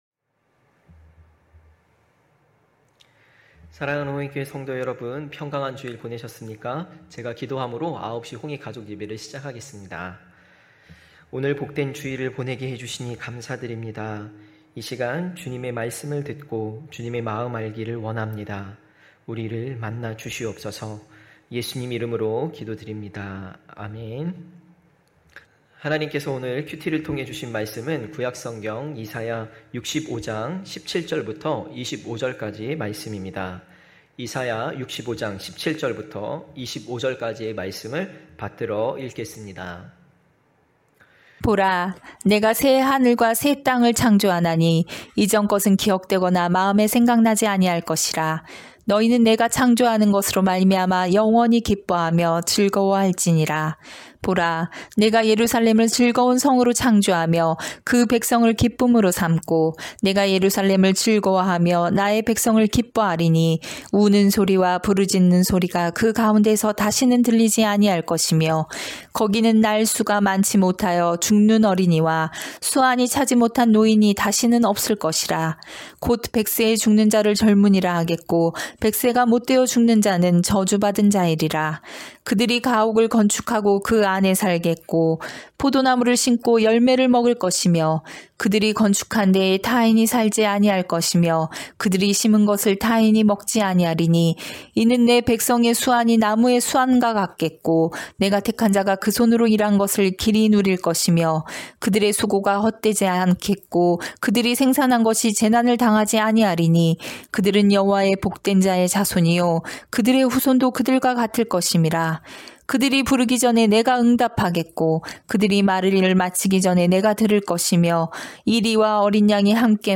9시홍익가족예배(8월15일).mp3